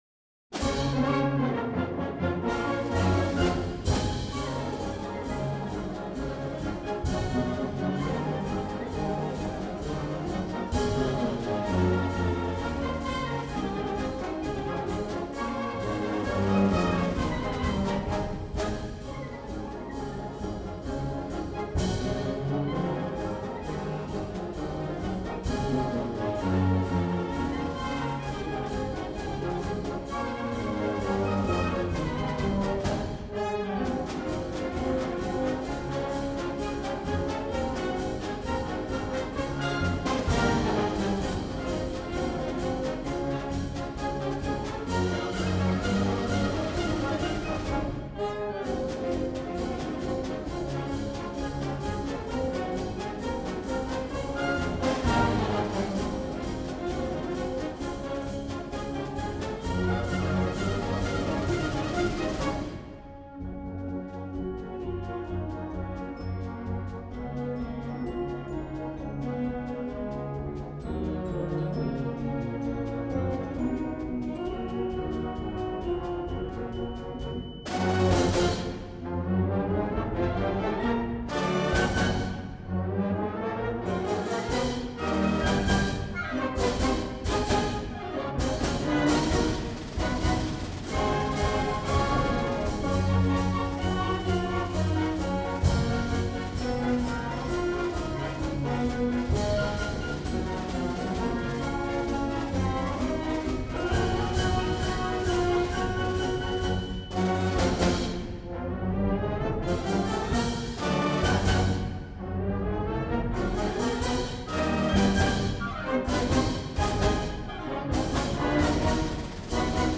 The Slippery Rock University Symphonic Wind Ensemble | KLN Islandora